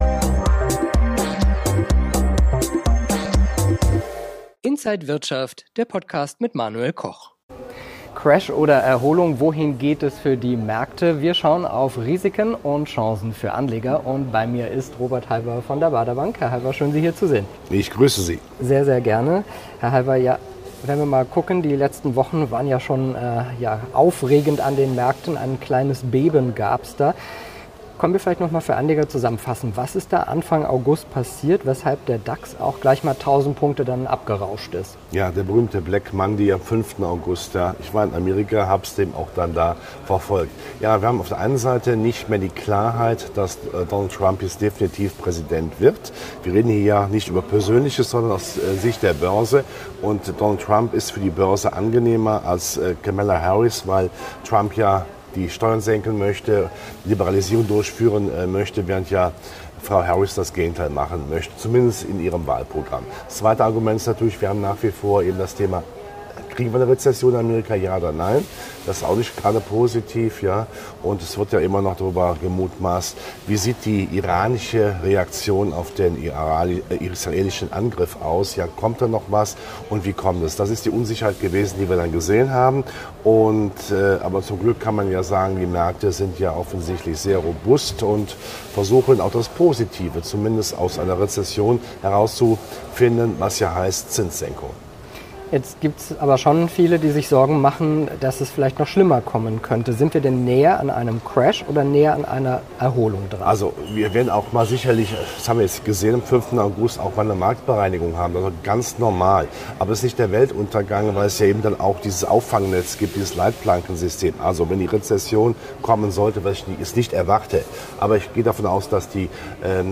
Alle Details im Interview von Inside